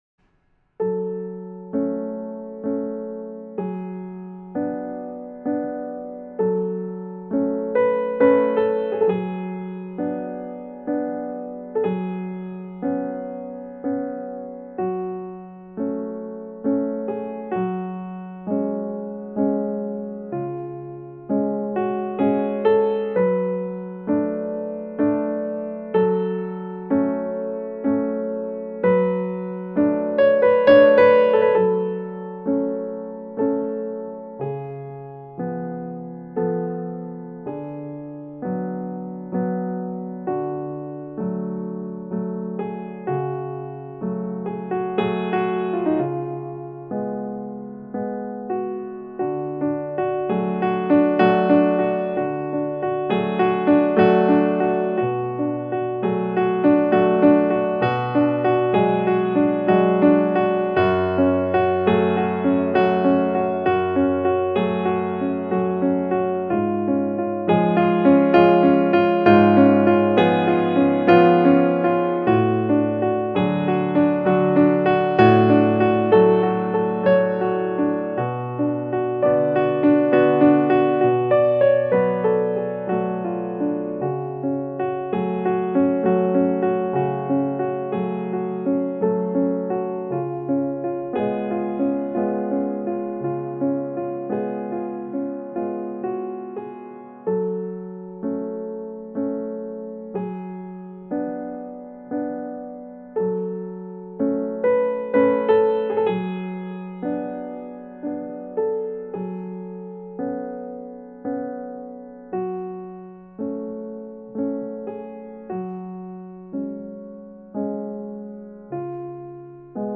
一张无比适合这个朦朦雨季的作品，动听的曲调中散发着新古典哀怨惆怅的气质。
作品几乎都是由钢琴和弦乐构成，只有其中两首用到了点点的采样，十分的简洁、干净，但是这样的器乐运用却并不显得乏味单调